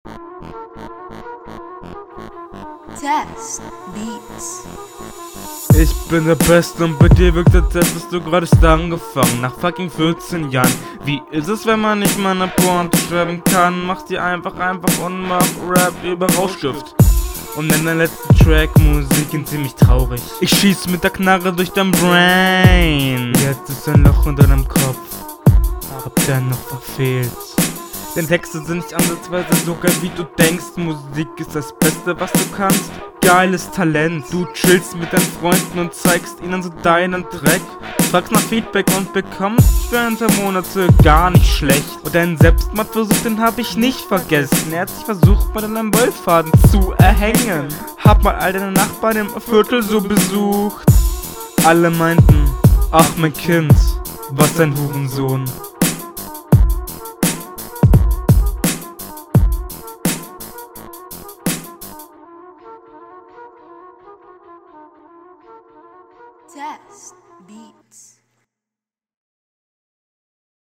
Flowlich auch hier noch relativ unsicher, da die Routine fehlt.
Beat und Stimme ist bei deiner Hinrunde nicht im Einklang.
Du solltest für den Anfang vielleicht eher leichtere Beats picken, der ist von der Geschwindigkeit …